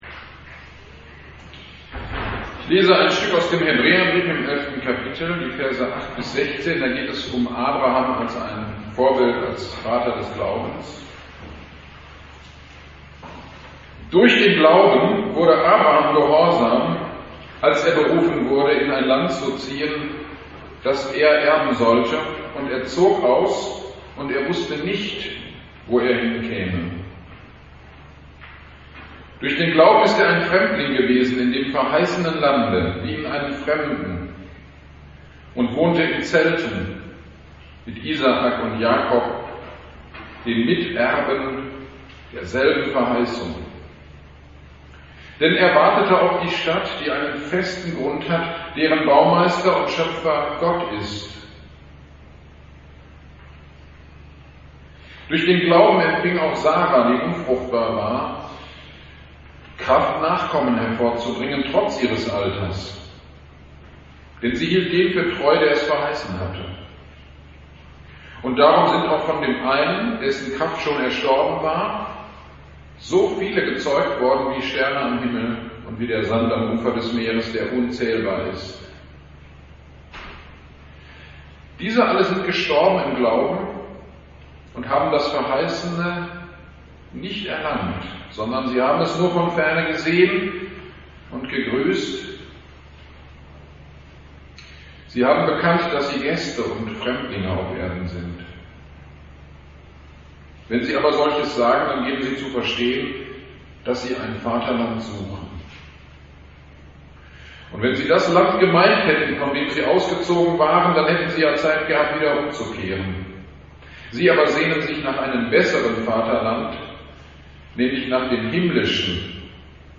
GD am 18.09.22: Predigt zu Hebräer 11,8-16 - Abrahams Glaube - Kirchgemeinde Pölzig